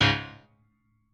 piano1_7.ogg